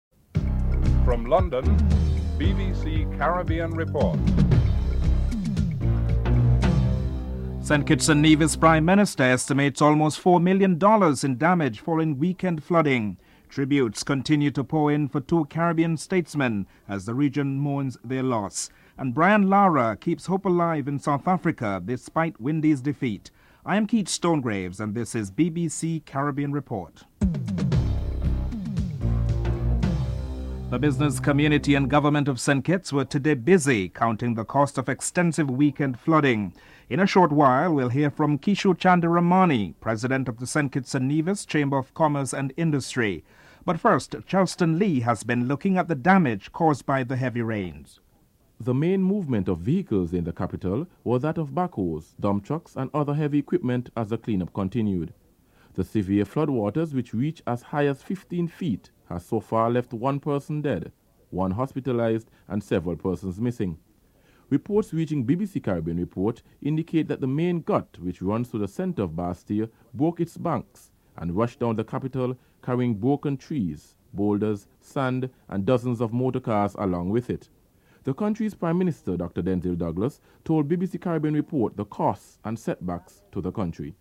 1. Headlines (00:00-00:30)
7. Brian Lara keeps hope alive in South Africa despite Windies defeat. Captain Brian Lara is interviewed (12:49-15:28)